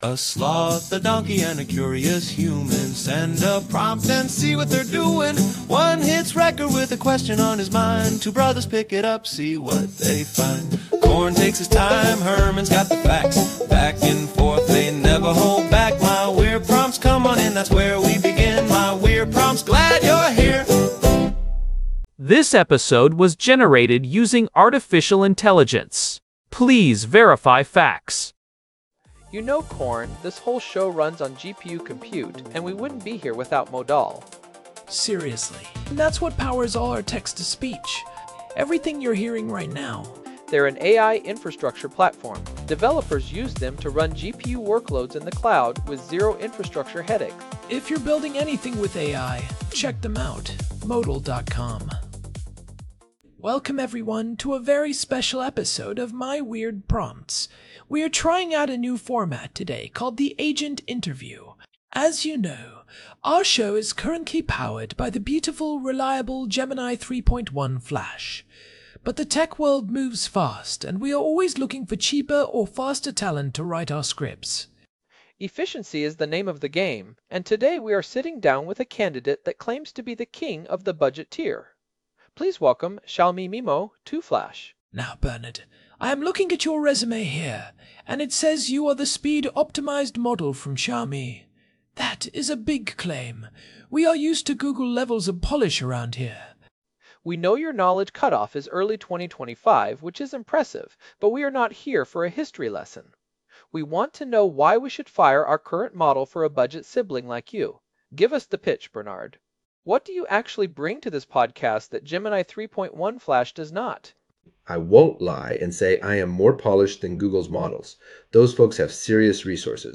AI-Generated Content: This podcast is created using AI personas.
xiaomi-mimo-flash-interview.m4a